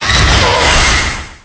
Cri de Melmetal dans Pokémon Épée et Bouclier.